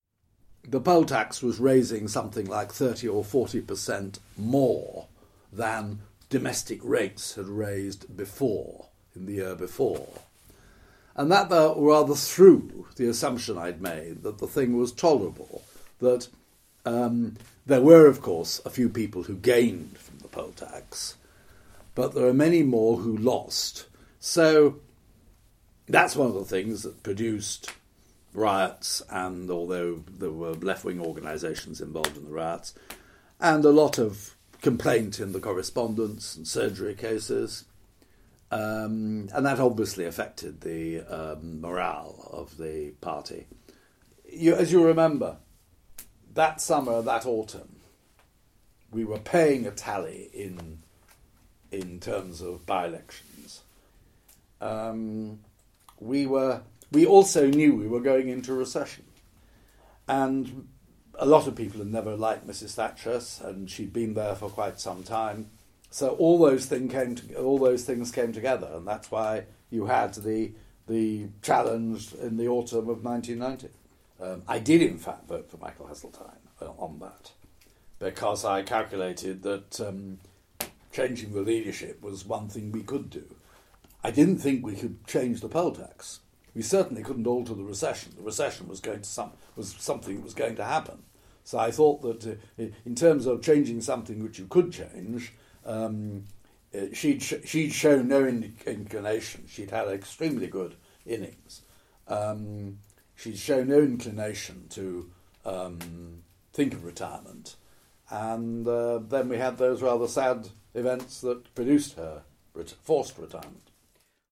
This is reflected in many of our oral history project interviews with former MPs.
For David Nicholson, MP for Taunton, his decision to vote against Thatcher combined misgivings about the poll tax, the government’s growing unpopularity and her leadership style, as he explains in this clip: